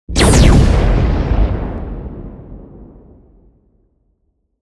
جلوه های صوتی
دانلود صدای رعدو برق 11 از ساعد نیوز با لینک مستقیم و کیفیت بالا
برچسب: دانلود آهنگ های افکت صوتی طبیعت و محیط دانلود آلبوم صدای رعد و برق از افکت صوتی طبیعت و محیط